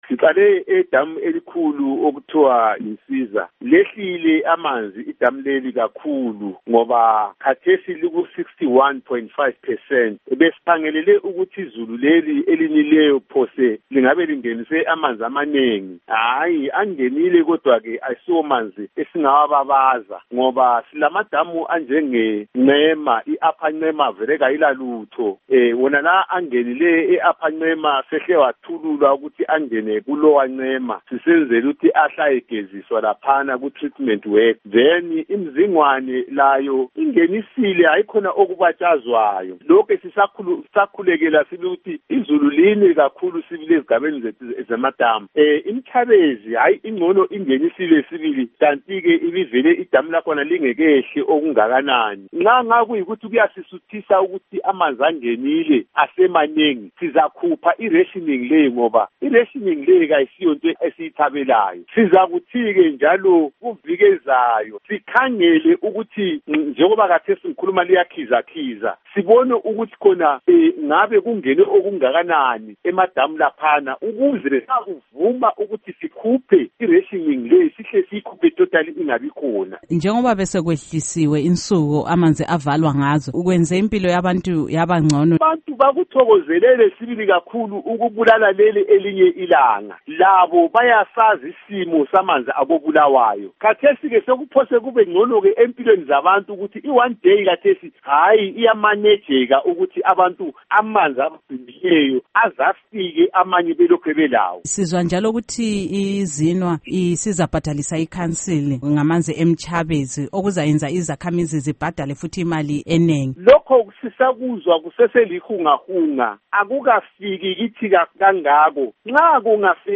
Ingxoxo LoKhansila Gideon Mangena